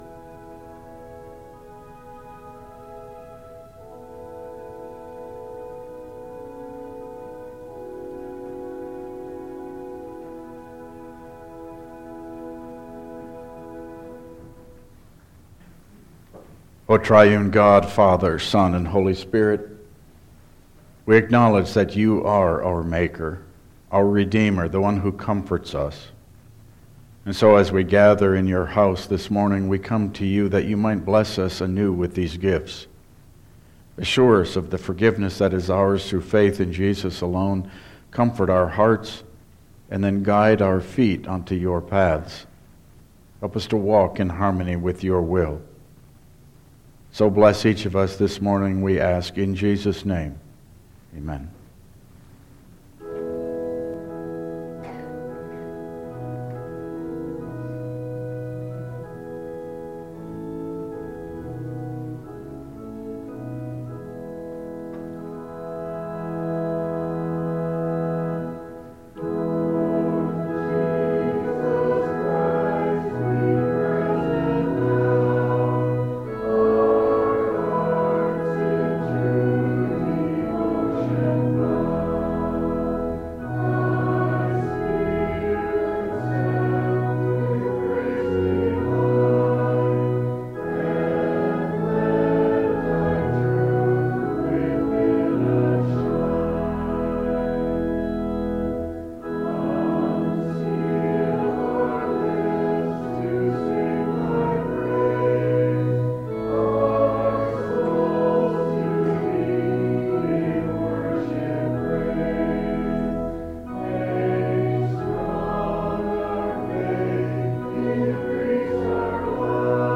Download Files Printed Sermon and Bulletin
Service Type: Regular Service